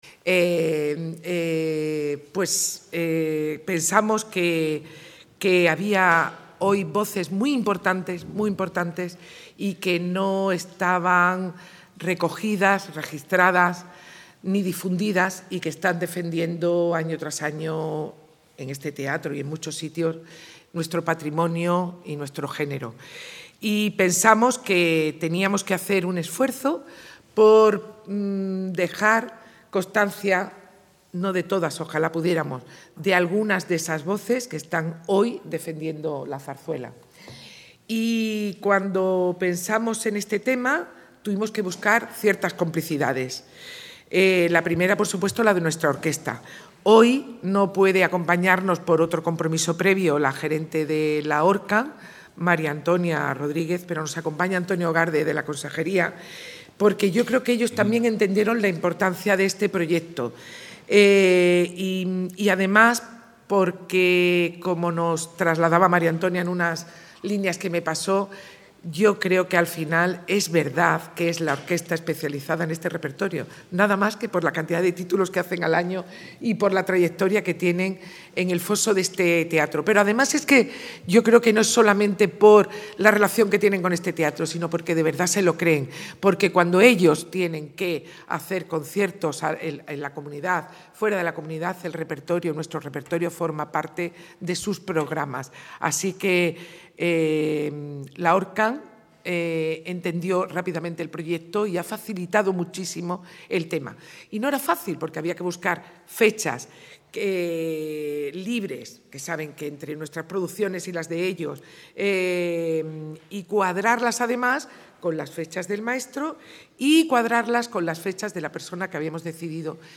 Grabado en noviembre de 2025, el proyecto destaca la vigencia de la zarzuela y su capacidad para seguir conectando con el público actual.